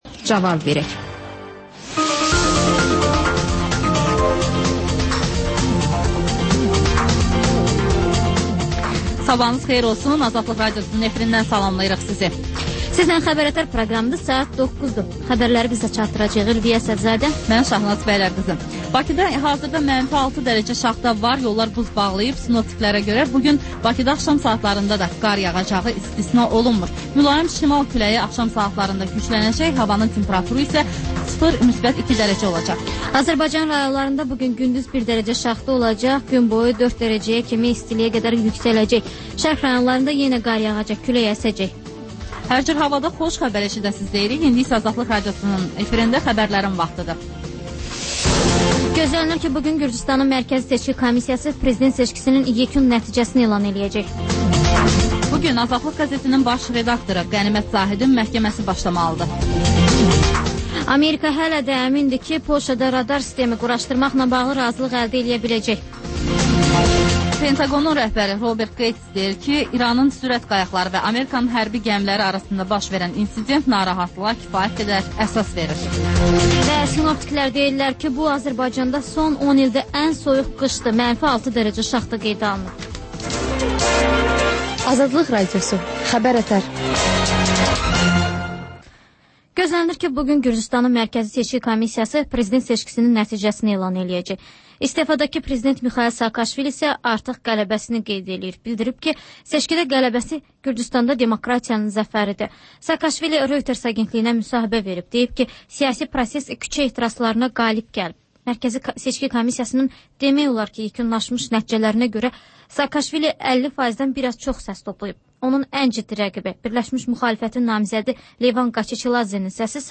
Xəbər-ətər: xəbərlər, müsahibələr, sonra TANINMIŞLAR verilişi: Ölkənin tanınmış simalarıyla söhbət